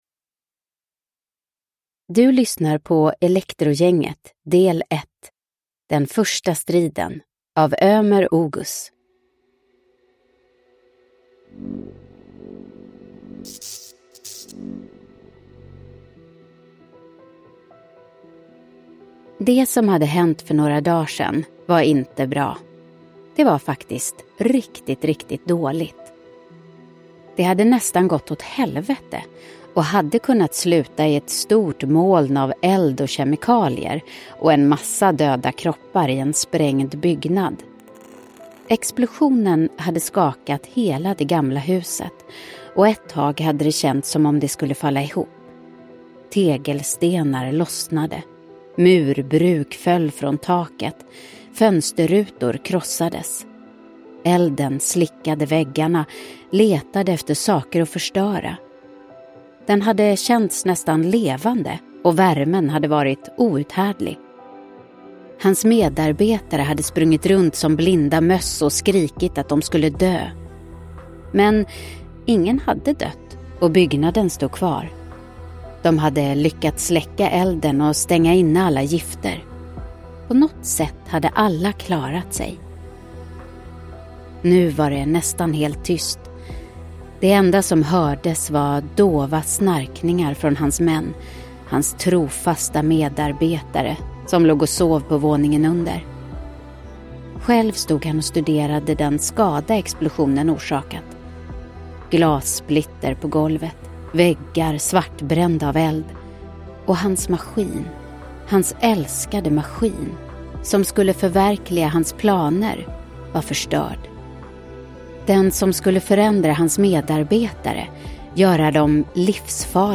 Den första striden – Ljudbok – Laddas ner